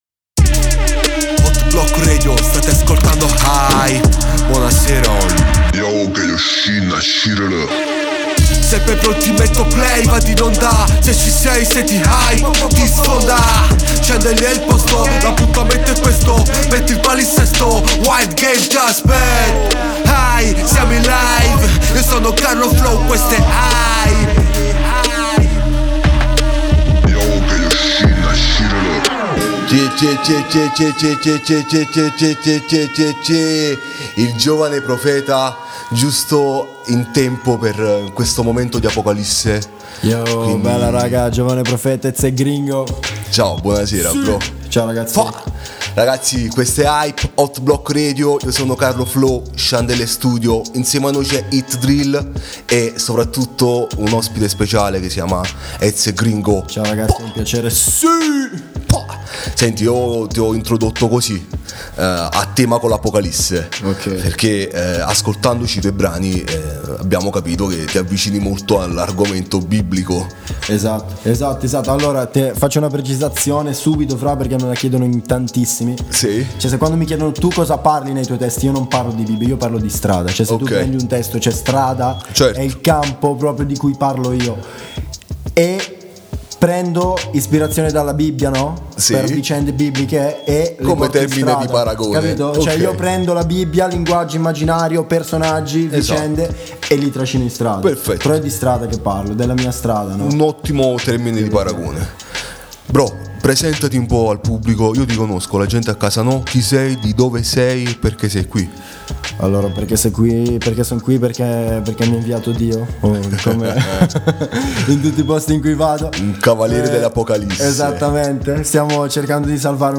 HYPE è il nostro programma con ospiti, interviste, skit, musica e confronti, tutto concentrato sui nuovi talenti italiani
INTERVISTA